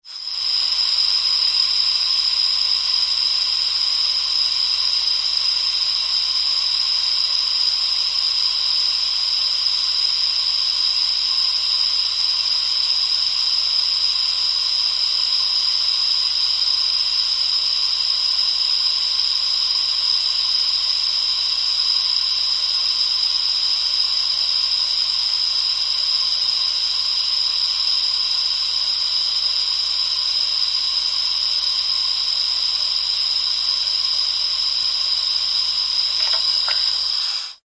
Oxygen Tank; Releases 3; One Long, Oxygen Tank Release; Turn On / Gas Hiss / Turn Off, Close Perspective. Pressurized Gas.